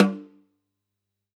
Index of /musicradar/Kit 1 - Acoustic close
CYCdh_K1close_SnrOff-07.wav